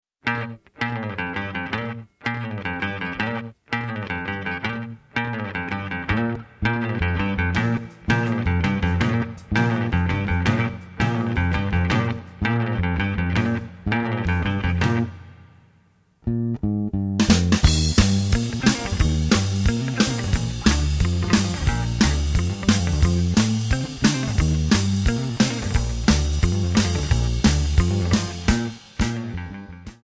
Bb
MPEG 1 Layer 3 (Stereo)
Backing track Karaoke
Pop, Oldies, 1950s